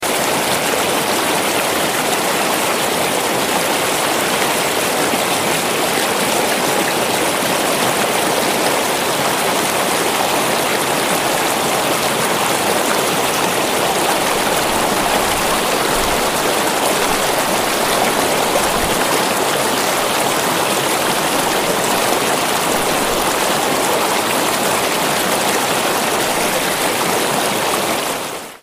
running-water-sound_14246.mp3